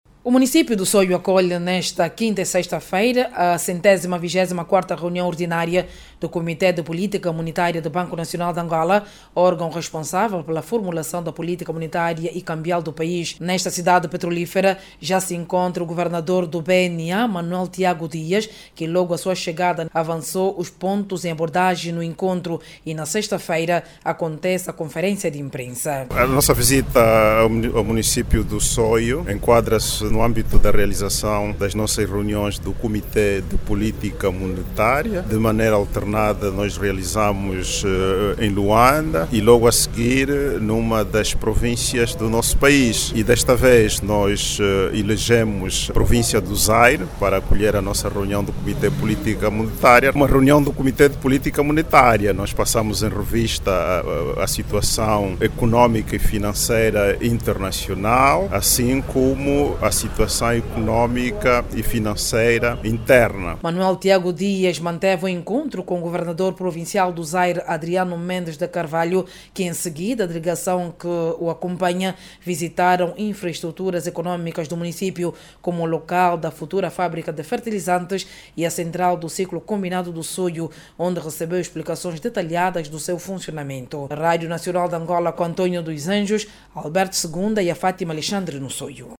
O encontro, a ser orientado pelo Governador do BNA, Manuel Tiago Dias, vai analisar o desempenho dos indicadores macroeconómicos da economia nacional. Clique no áudio abaixo e ouça a reportagem